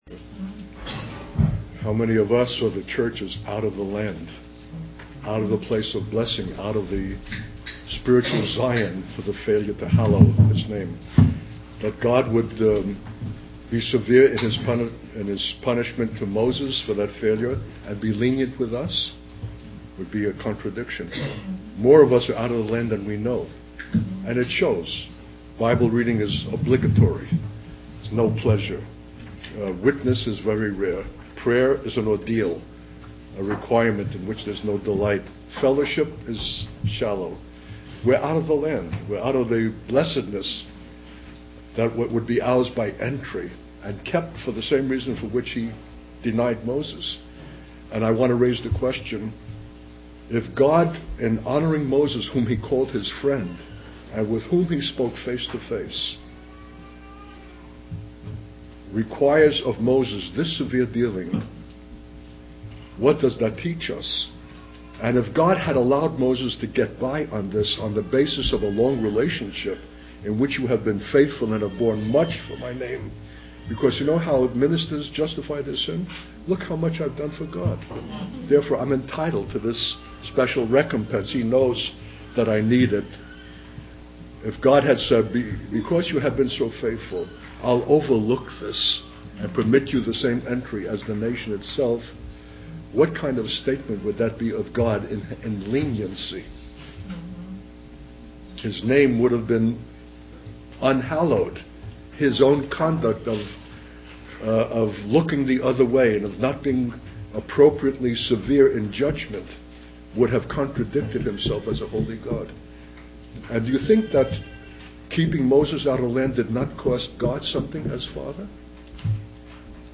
In this sermon, the speaker discusses the failure of a shepherd and how it reflects the failure of the church body.